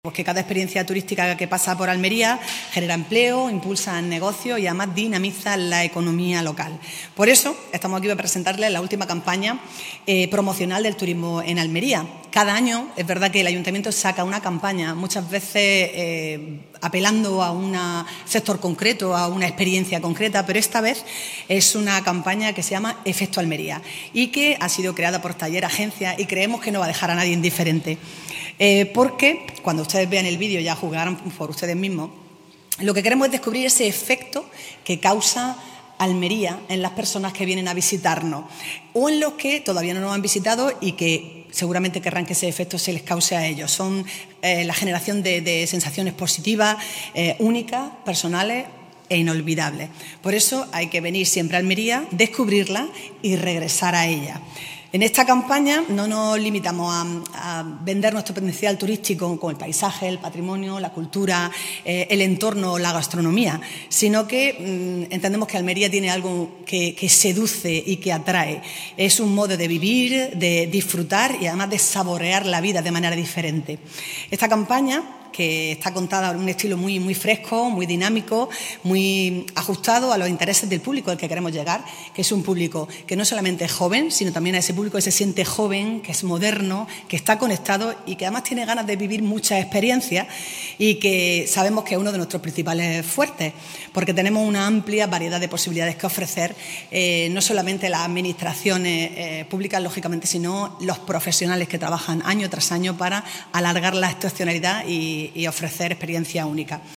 La alcaldesa, María del Mar Vázquez, desgrana la última acción promocional turística junto a la proyección del ‘spot’ donde se muestran algunos de los lugares más emblemáticos de la ciudad y la forma de vivir y sentir Almería
ALCALDESA-3.mp3